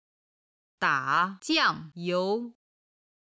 打酱油　(dǎ jiàng yóu)　自由で勝手気まま